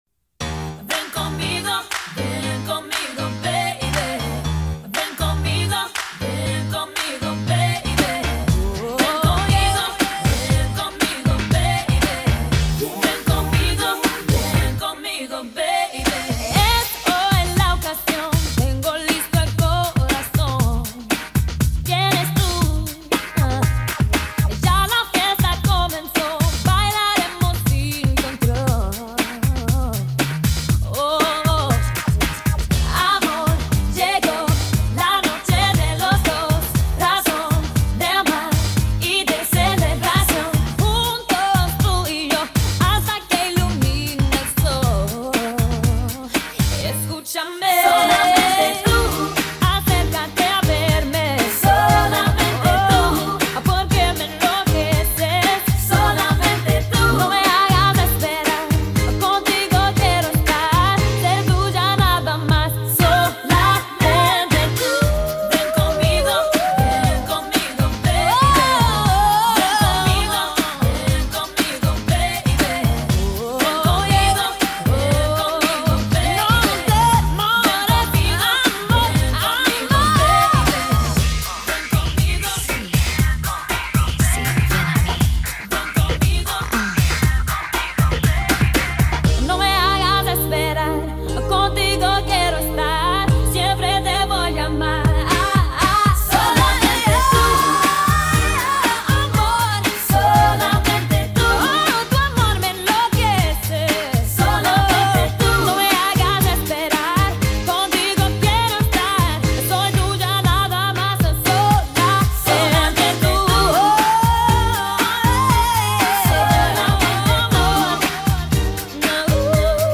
BPM119
Audio QualityPerfect (High Quality)
Comments*It's real BPM is 118.880